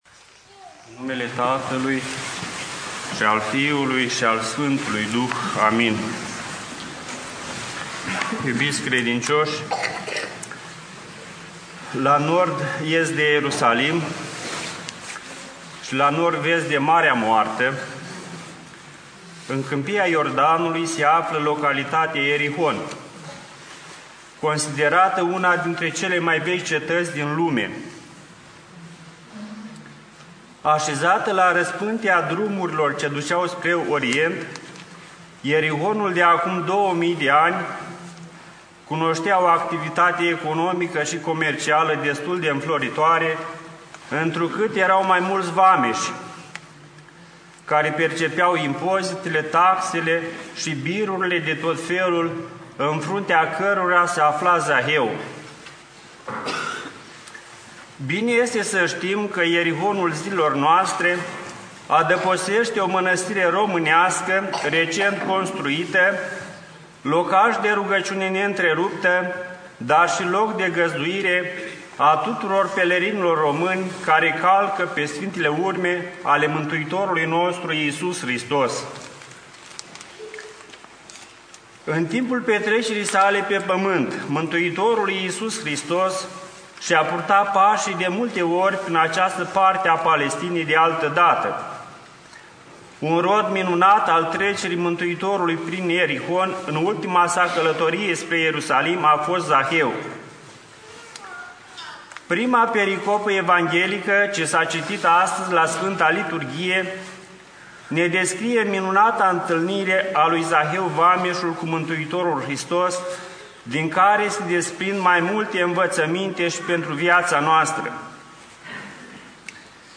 Predică PF Daniel (2003) Predică IPS Bartolomeu Anania (2001) Cuvânt după Sfânta Liturghie Arhim.